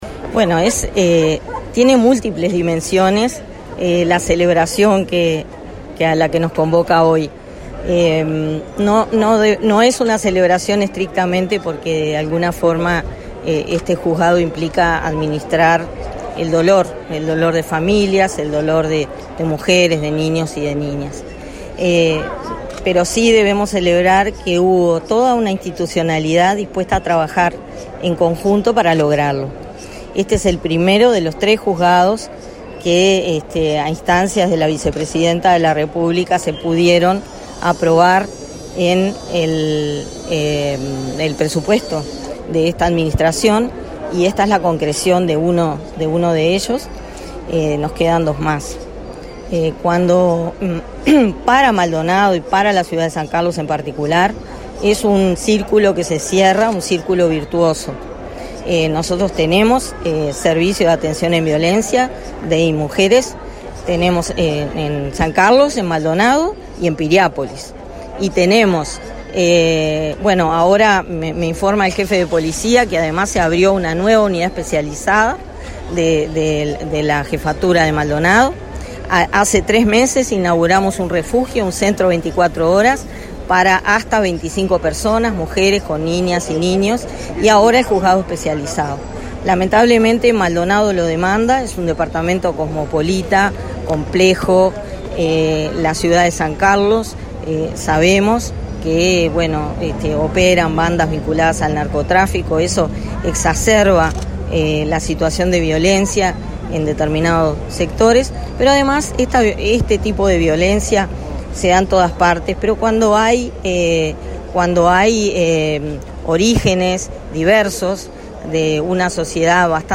Declaraciones a la prensa de la directora de Inmujeres